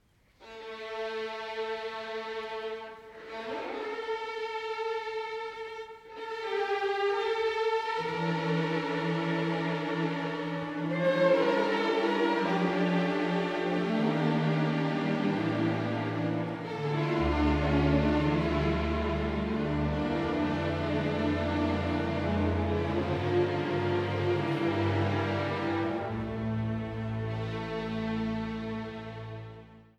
repräsentative Live-Aufnahmen
Notturno